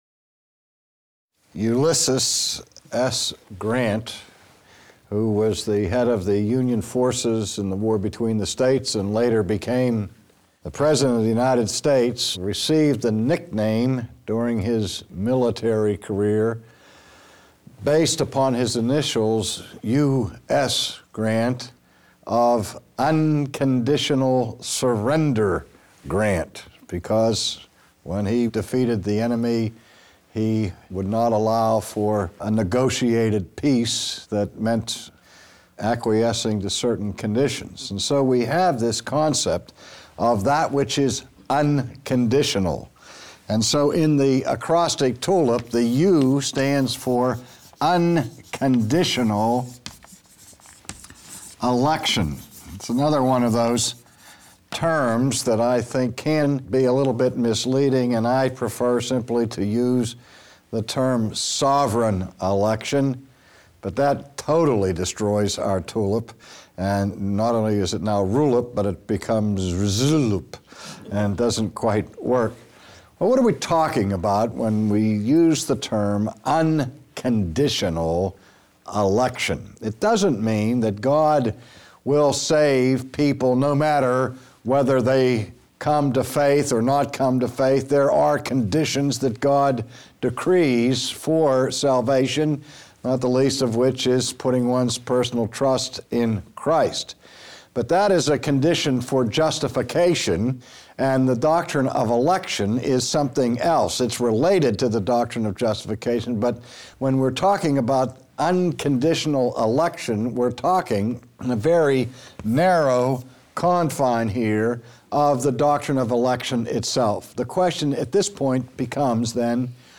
Lecture 9, Unconditional Election : Upon what condition did God elect sinners to salvation?